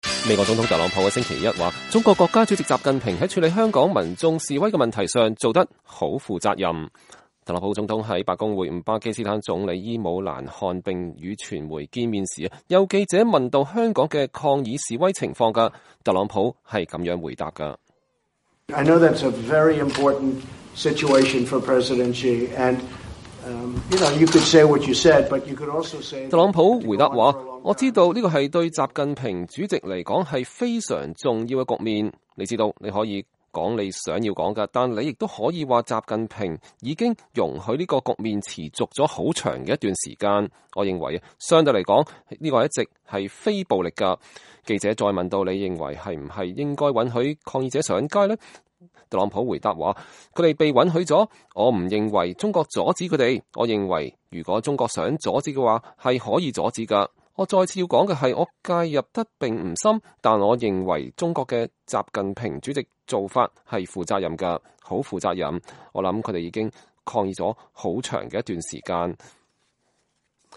特朗普總統在白宮會晤巴基斯坦總理伊姆蘭·汗並與傳媒見面時，有記者問到香港的抗議示威。